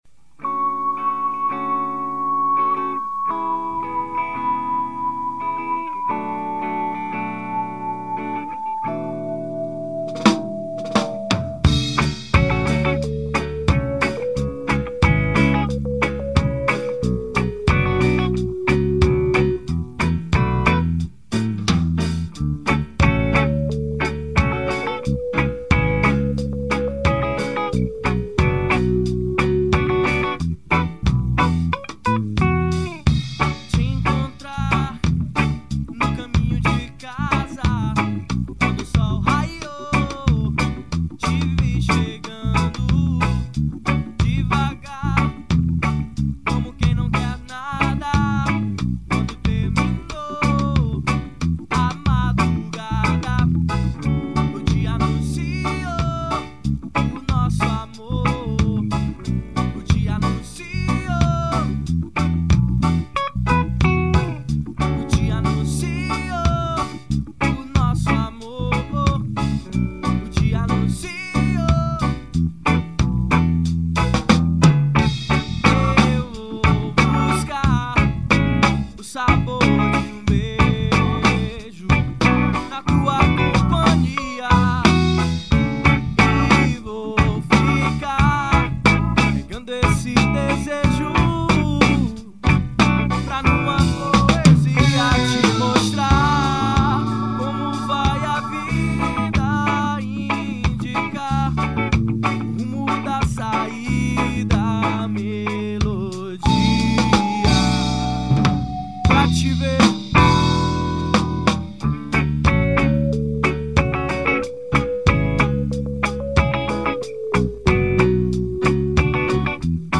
1990   04:06:00   Faixa:     Reggae